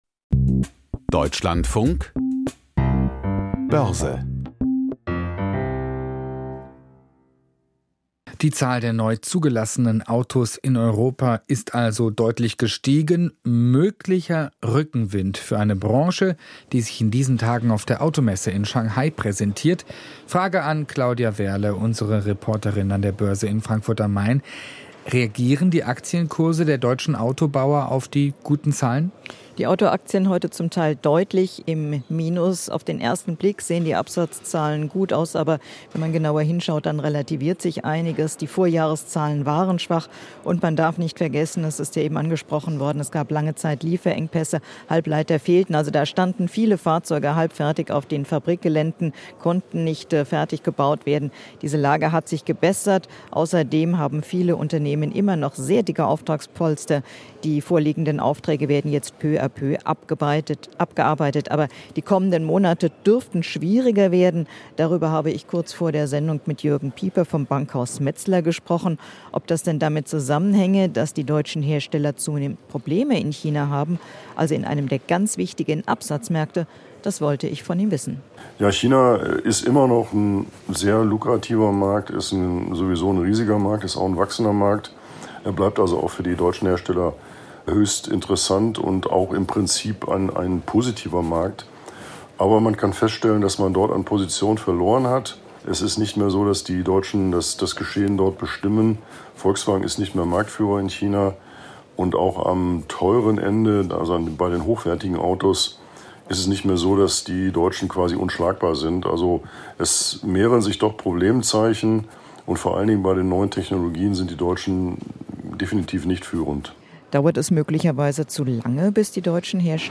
Börsenbericht aus Frankfurt